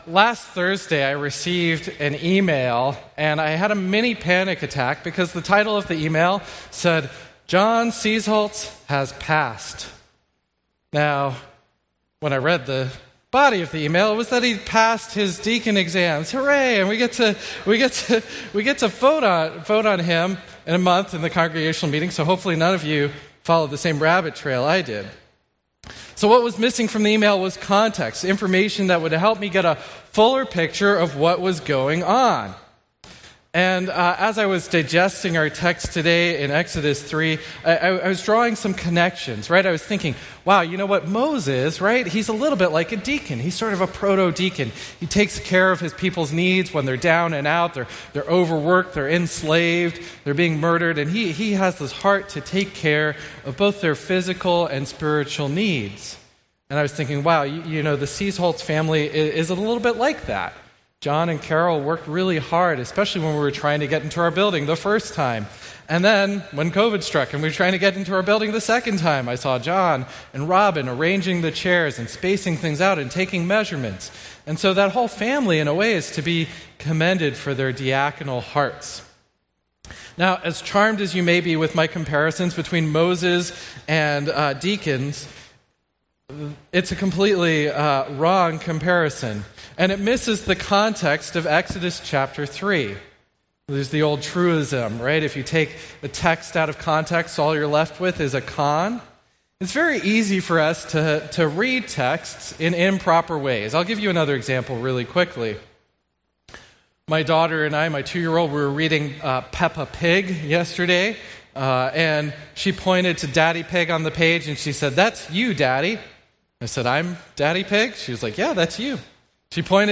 A message from the series "Sojourners: Exodus ."